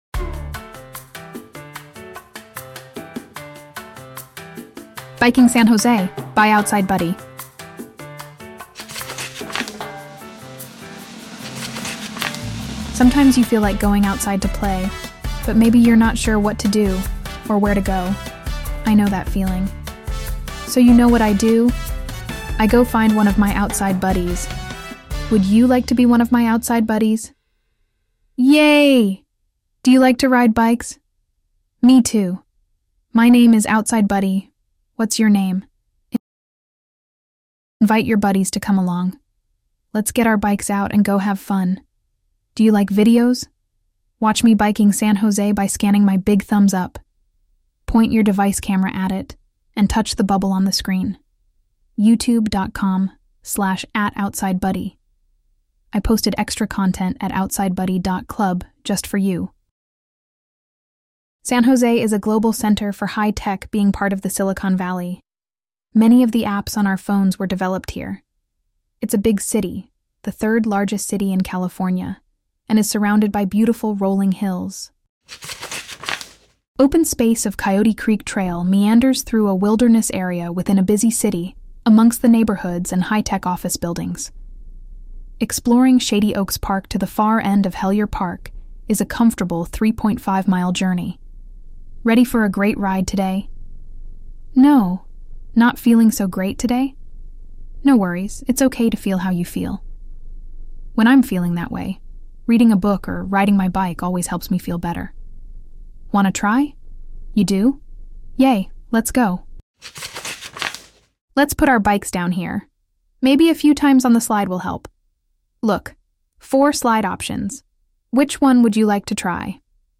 Free AudioBook!